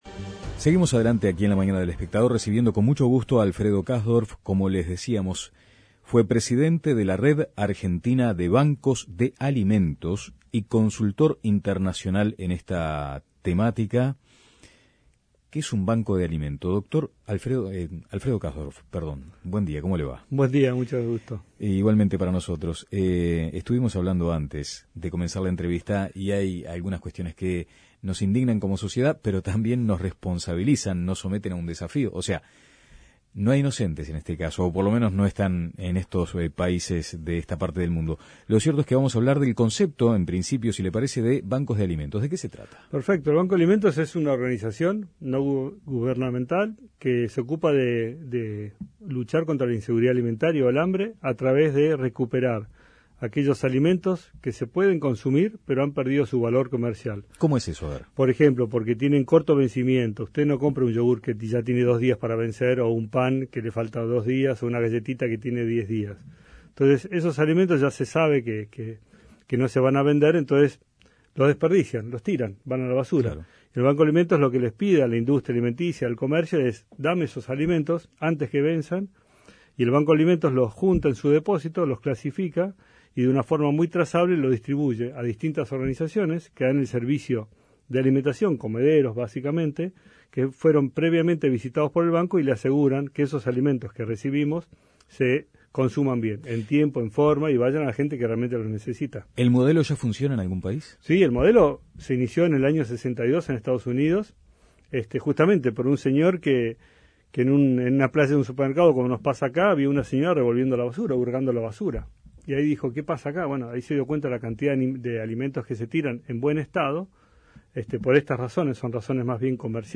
ENTREVISTA EN LA MAÑANA Bancos de alimentos como "una solución" para combatir el hambre Imprimir A- A A+ En el mundo más de 800 millones de personas sufren de hambre.